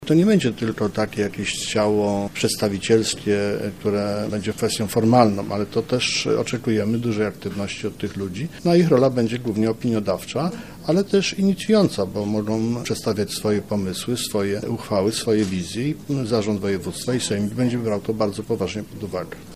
– Młodzieżowy Sejmik to odpowiedź samorządu Mazowsza na prośby zgłaszane przez młodych mieszkańców regionu, którzy chcą brać sprawy we własne ręce – mówi marszałek województwa mazowieckiego Adam Struzik.